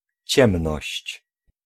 Ääntäminen
IPA : /ˈdɑɹk/